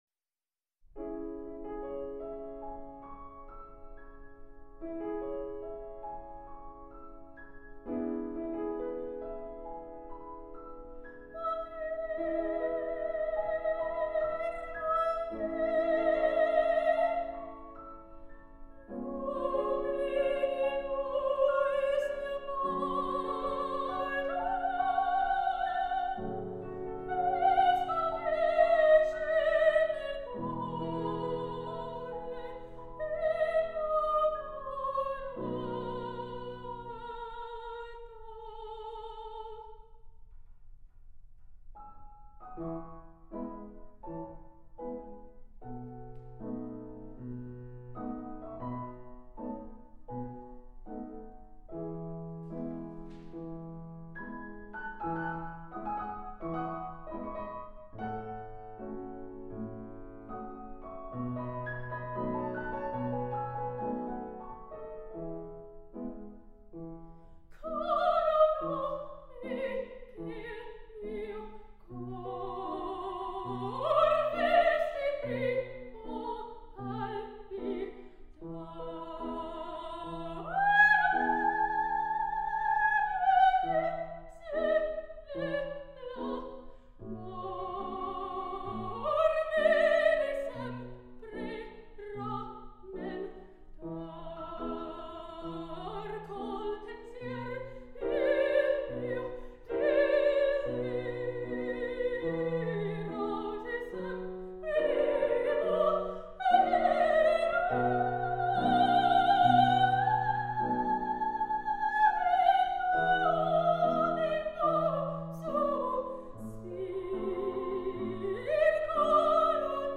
Denver Opera Singer 2
Denver-Opera-Singer-Caro_nome_.mp3